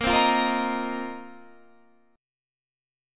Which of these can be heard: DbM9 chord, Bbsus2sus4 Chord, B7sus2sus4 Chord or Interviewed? Bbsus2sus4 Chord